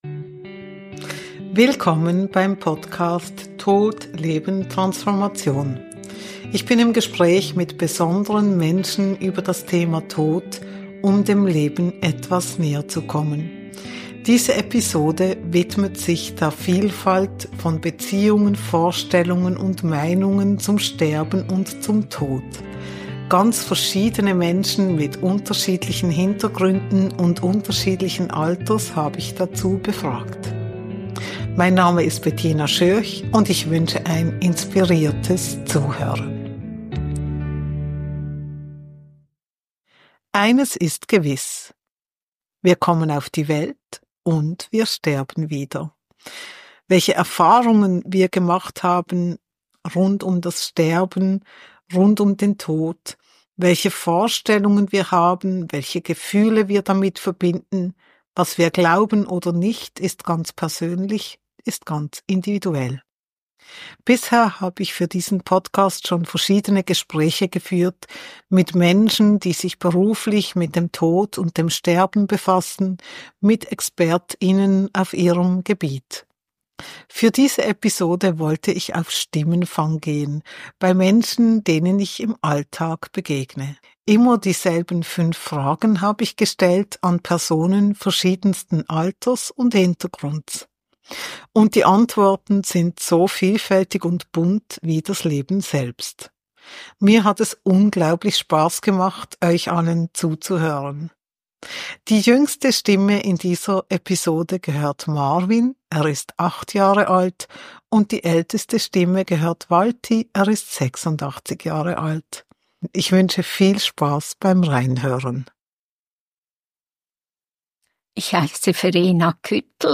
Bei dieser Episode wollte ich Menschen jeden Alters befragen.